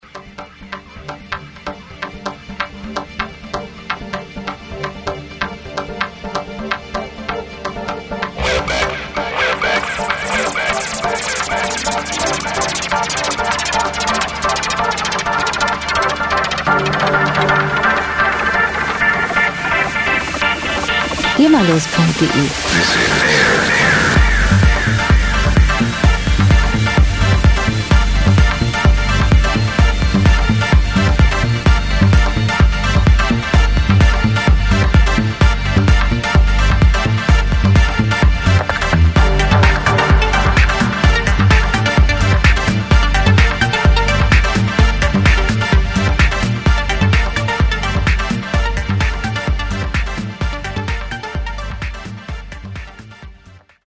• Space Techno